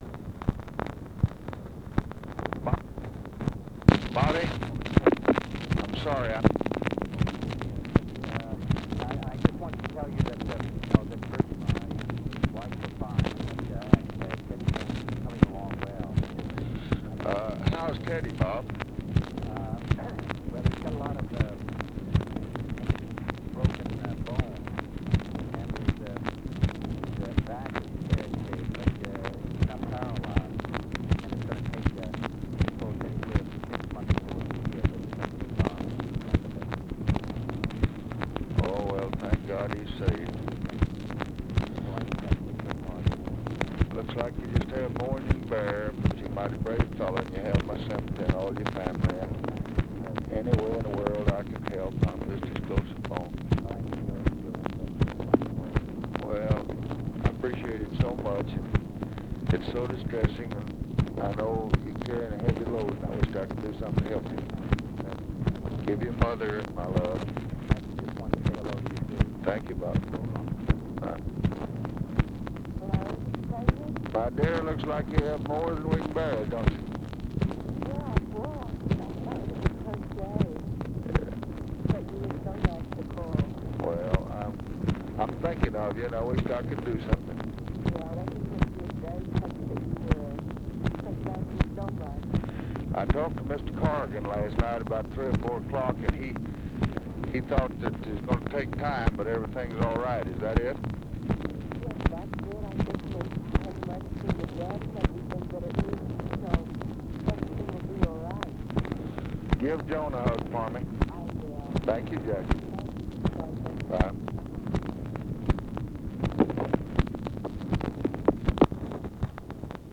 Conversation with ROBERT KENNEDY and JACQUELINE KENNEDY, June 20, 1964
Secret White House Tapes